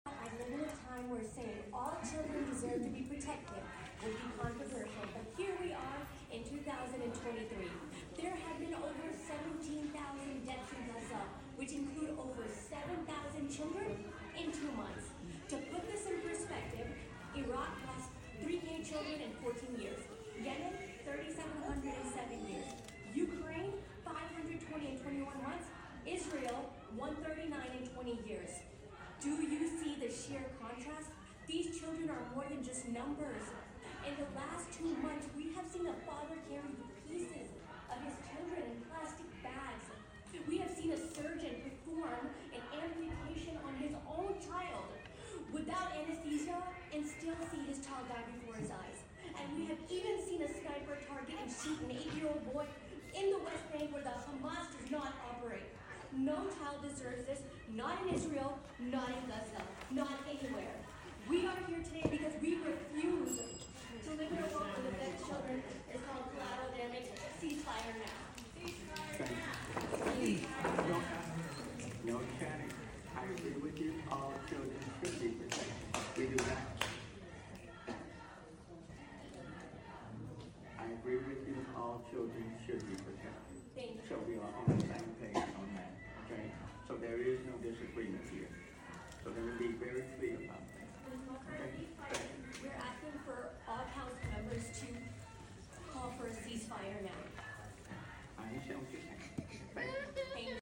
The people of Houston packed city hall and stand for human rights and a FREE PALESTINE!